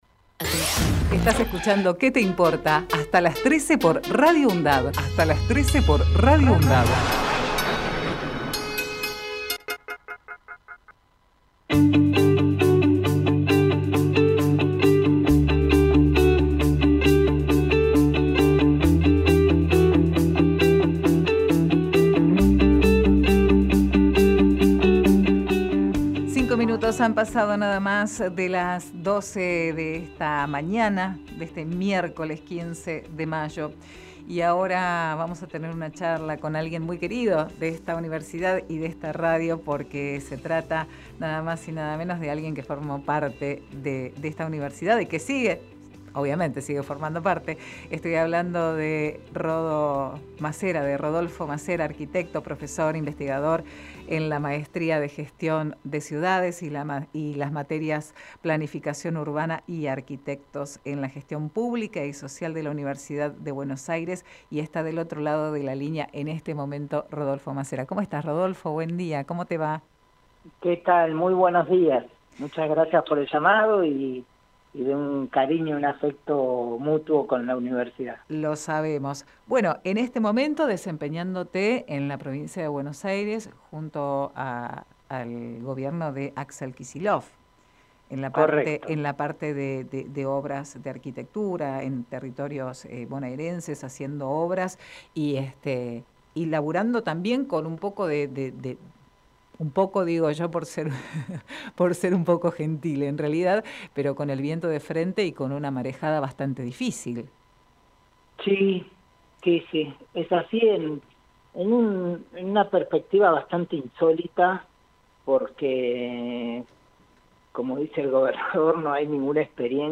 QUÉ TE IMPORTA - RODOLFO MACERA Texto de la nota: Compartimos entrevista realizada en "Que te Importa" a Rodolfo Macera, Fue director de Arquitectura en la Gestión de Axel Kicillof, luego estuvo a cargo del Área Técnica de Vivienda y ahora se desempeña como Director del Programa de Casas de la Provincia Archivo de audio: QUÉ TE IMPORTA - RODOLFO MACERA Programa: Qué te importa?!